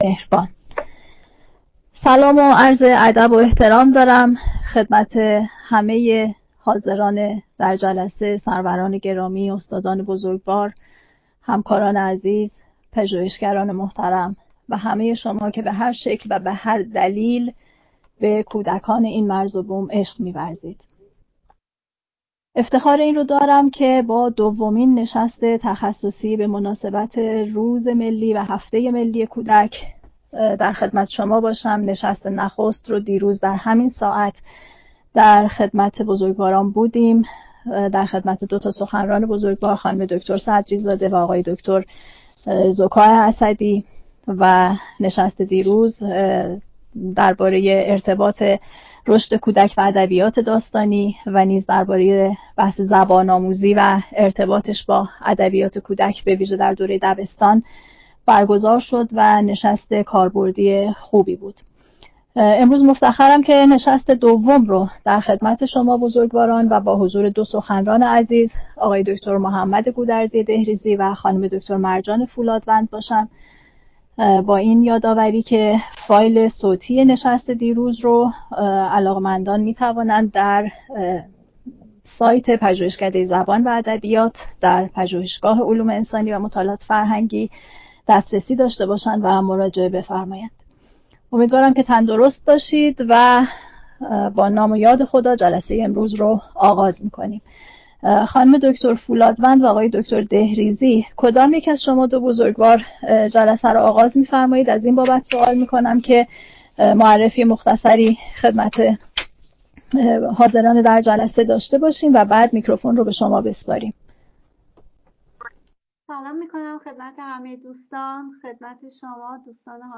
پژوهشکده‌ی زبان و ادبیات فارسی نشستی با عنوان زیر برگزار می‌کند: بزرگ‌داشت روز جهانی کودک سخنرانان